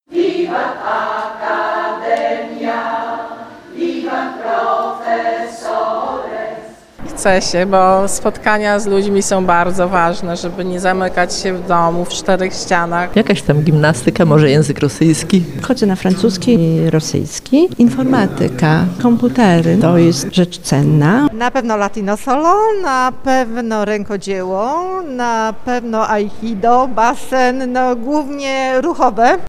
– Zdobywanie wiedzy jest dla nas czystą przyjemnością – mówią studentki Uniwersytetu Trzeciego Wieku.